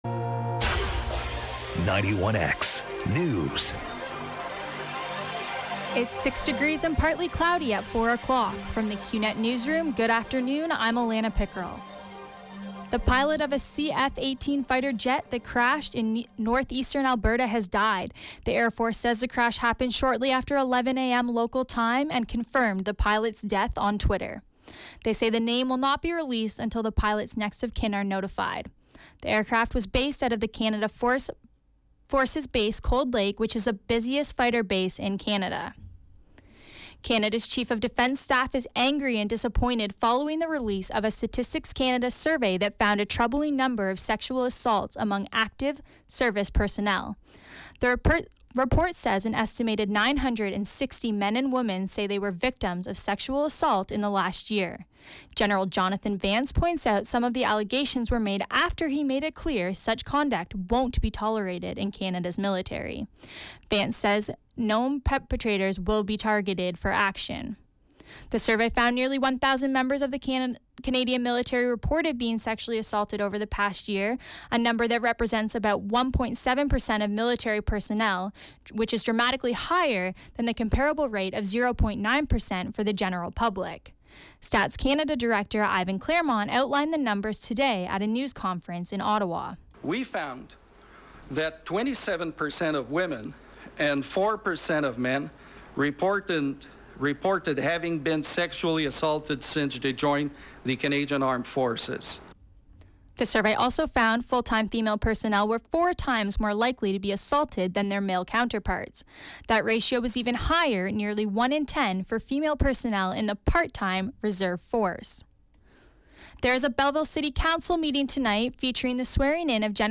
91X FM Newscast – Monday, Nov. 28, 2016, 4 p.m.